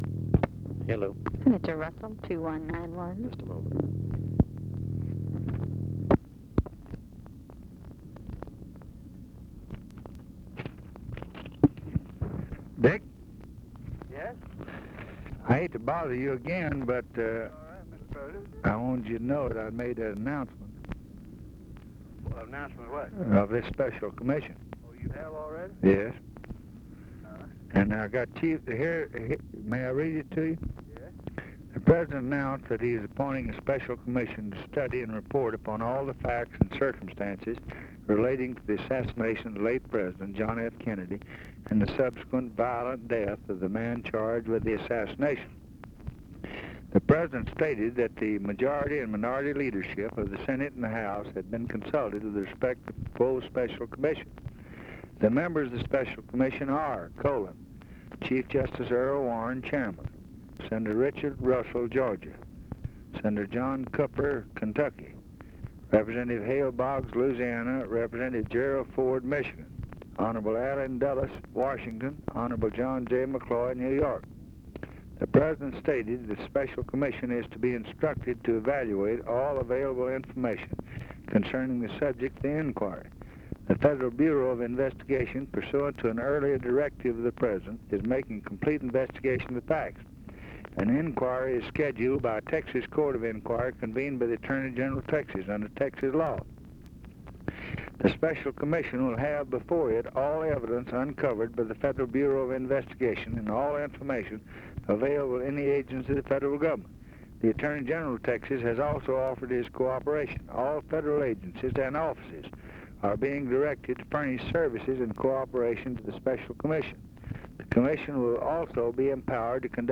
Conversation with RICHARD RUSSELL, November 30, 1963
Secret White House Tapes | Lyndon B. Johnson Presidency Conversation with RICHARD RUSSELL, November 30, 1963 Rewind 10 seconds Play/Pause Fast-forward 10 seconds 0:00 Download audio Previous Conversation with WILLIAM MCC.